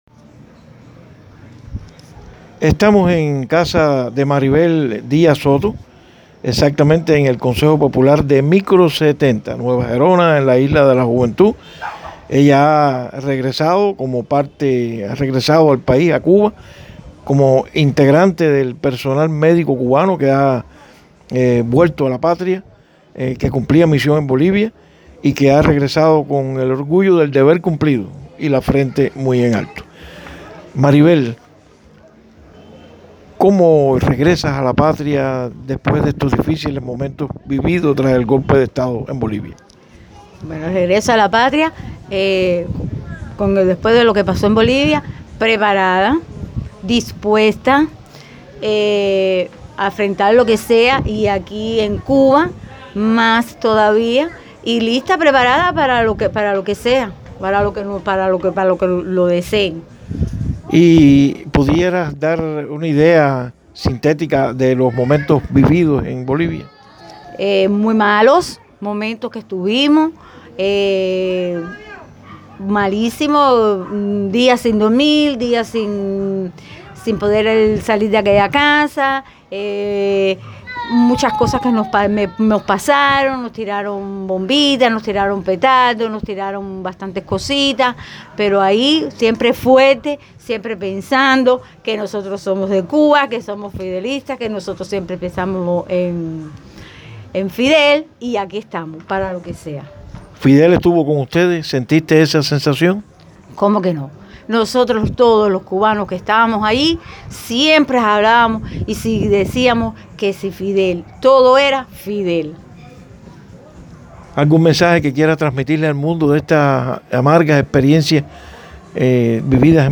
Entrevista en audio: